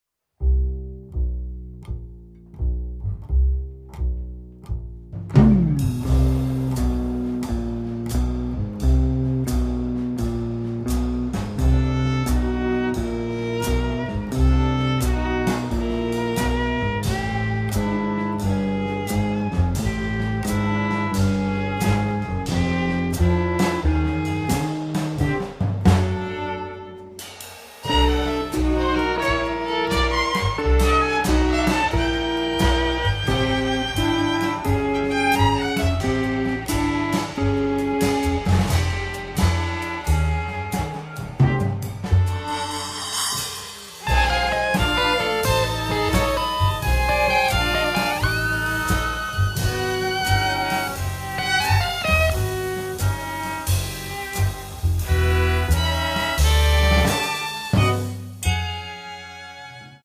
drums, percussion, glockenspiel
L'introduzione vede protagonisti contrabbasso e archi